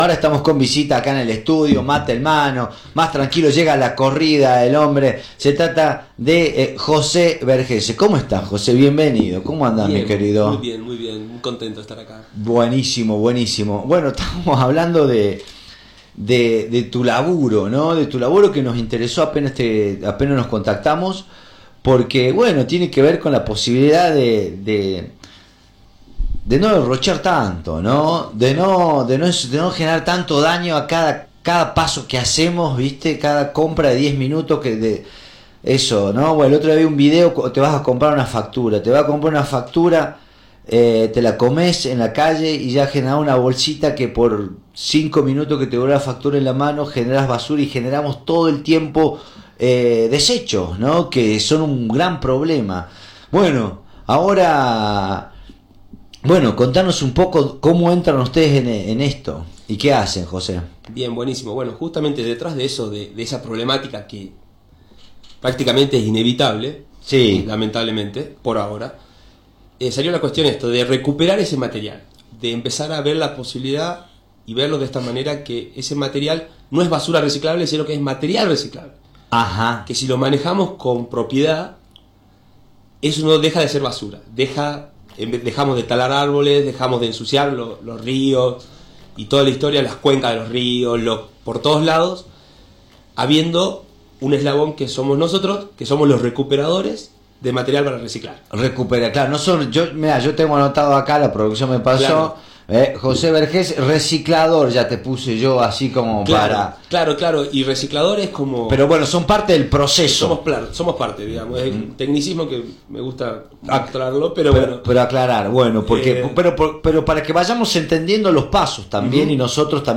en diálogo con Radio Dinamo se refirió a la actualidad del reciclado en Salta